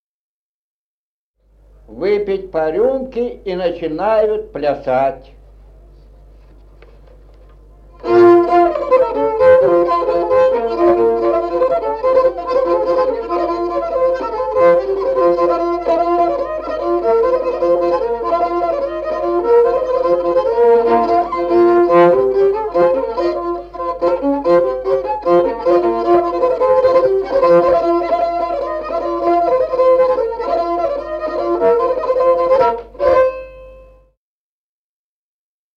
Музыкальный фольклор села Мишковка «Камарицкая», репертуар скрипача.